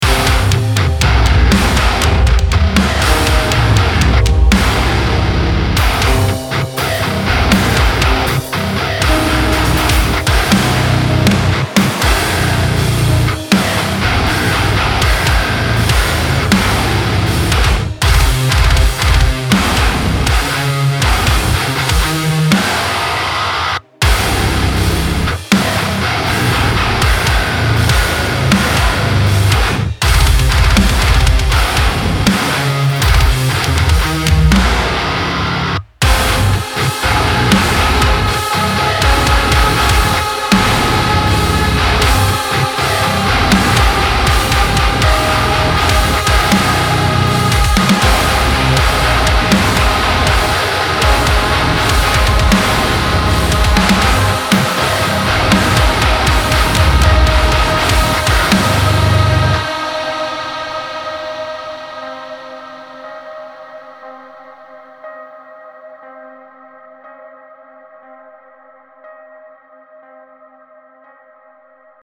Описание: Ударная установка